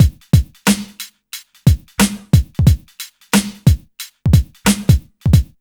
HM90BEAT2 -L.wav